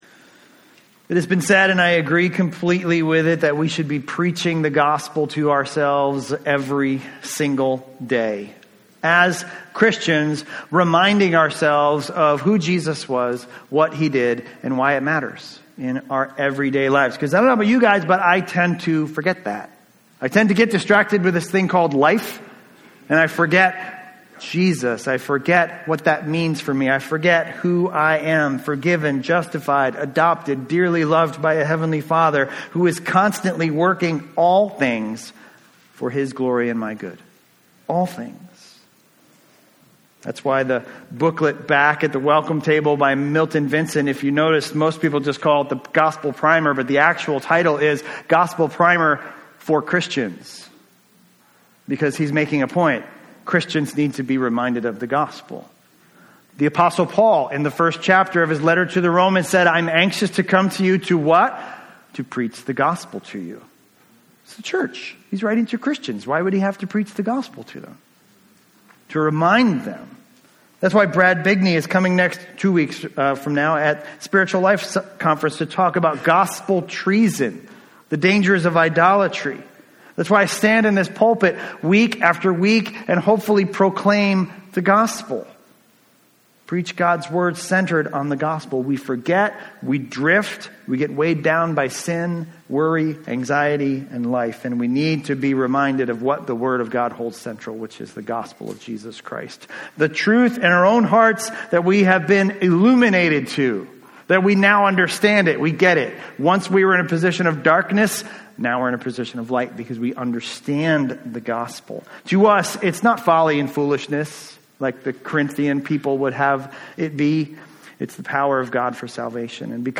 A message from the series "1 Corinthians." In 1 Corinthians 2:1-5 we learn that we must be resolved to let our faith rest in the power of God in the gospel.